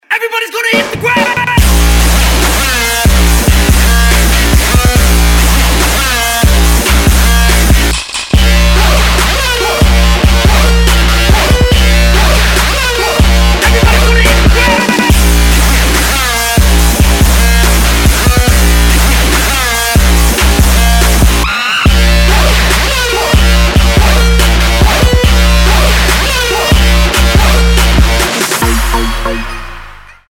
Trap
club
Dubstep